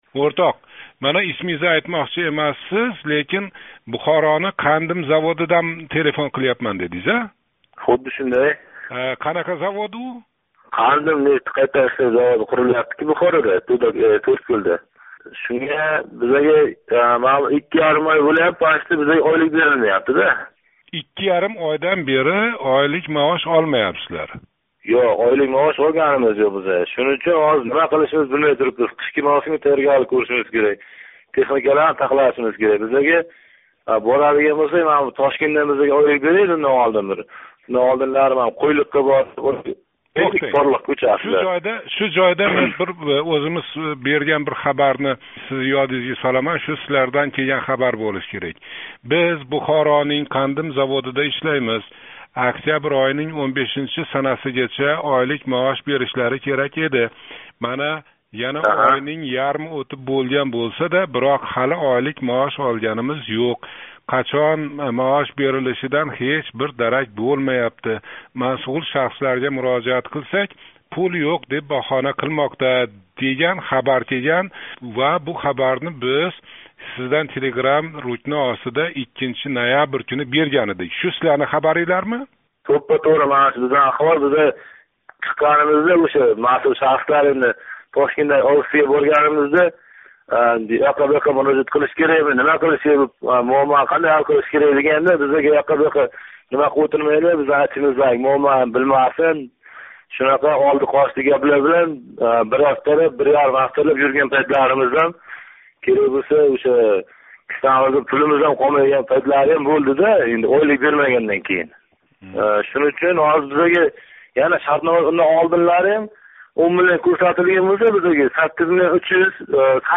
Эътиборингизга ишчининг айтганларини OzodMikrofon форматида, яъни қўшимча ўрганмай ҳавола қилаяпмиз.